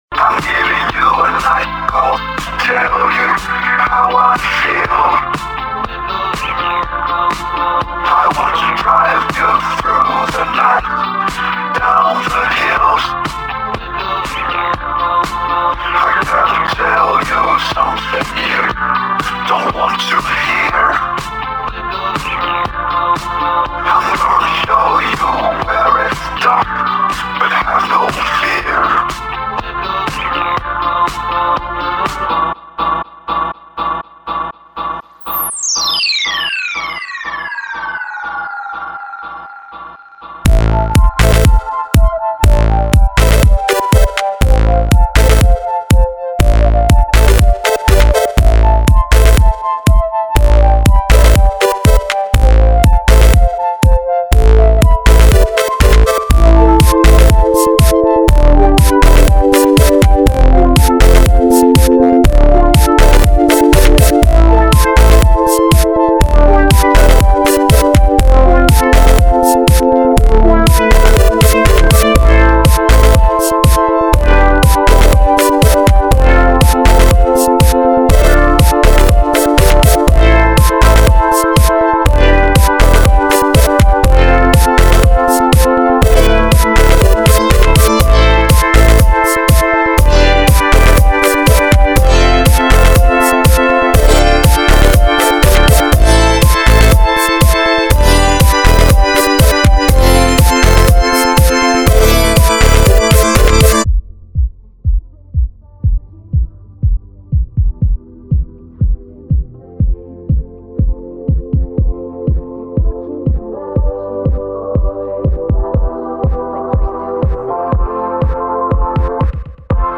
Really diggin this beat 🙂
vocals could be louder 😦